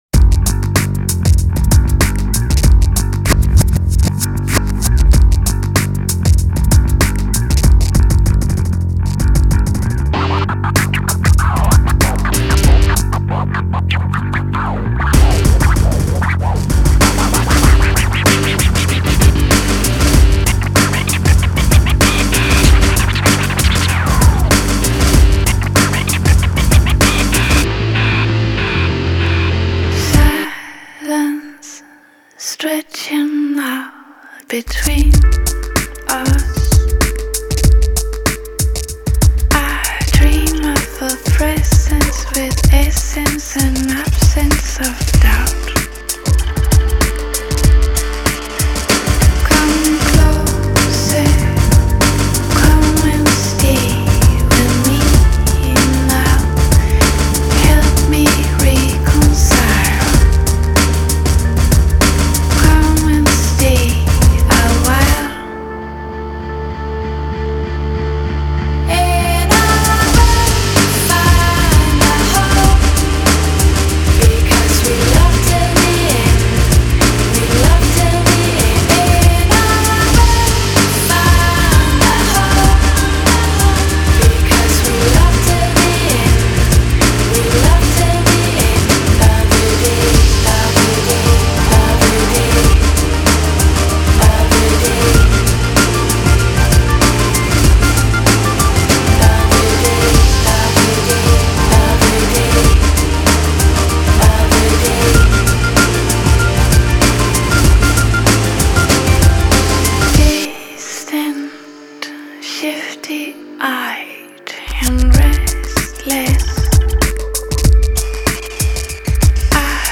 they make “melodic dreamy pop” music.